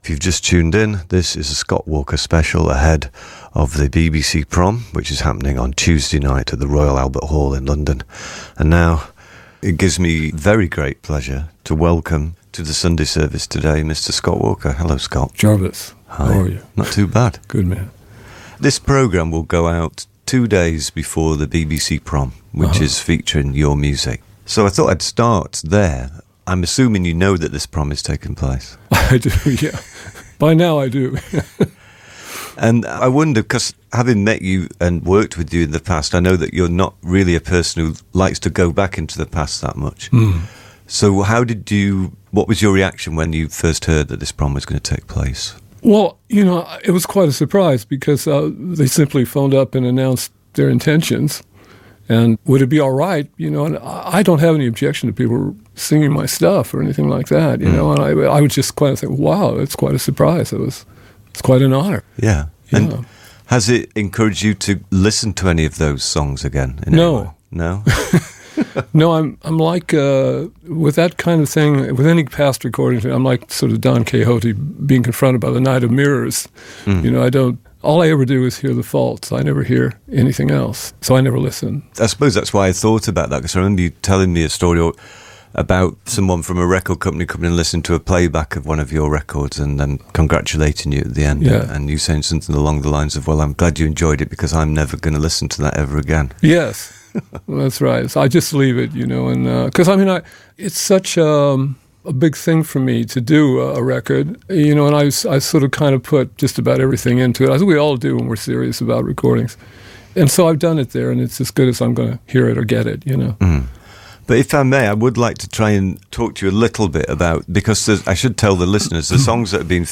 A Word Or Two With Scott Walker - (1943-2019) - Past Daily Tribute Edition - Jarvis Cocker sits down with Scott Walker to discuss his work in 2017.
Scott Walker – In Conversation with Jarvis Cocker – BBC 6 Music – July 23, 2017 – BBC Radio 6 –